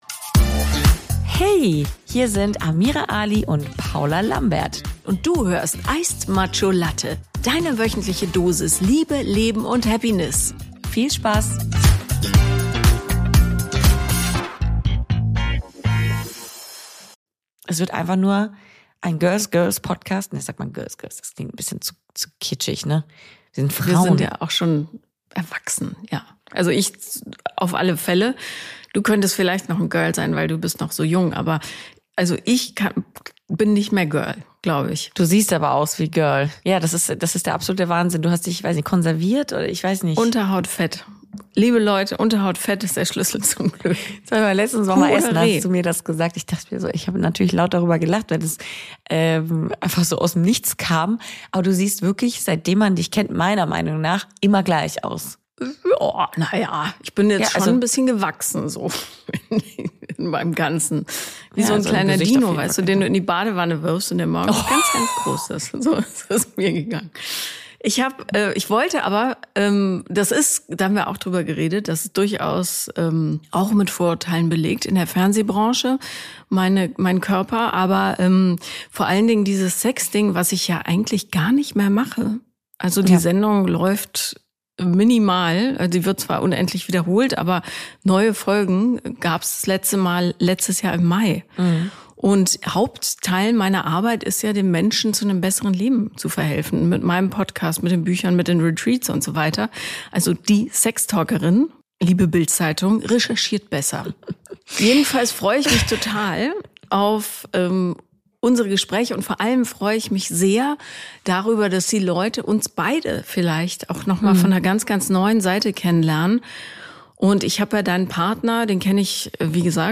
Zwei starke Frauen, zwei außergewöhnliche Lebensgeschichten: In